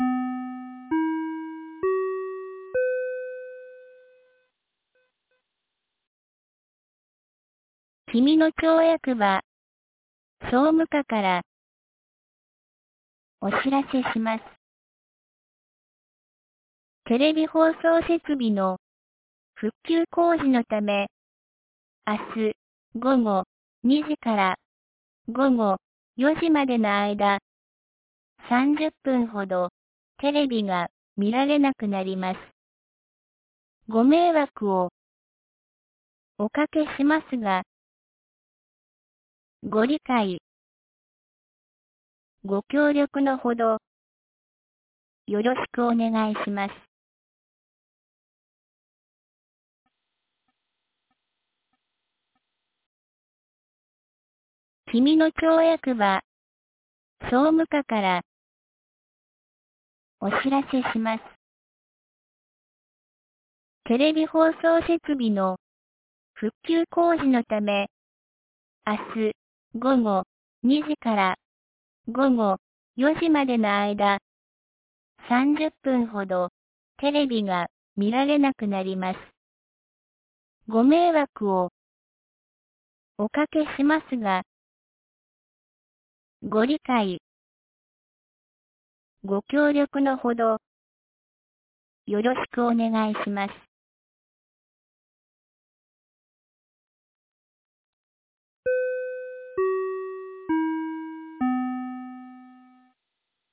2025年06月11日 15時06分に、紀美野町より上神野地区、国吉地区へ放送がありました。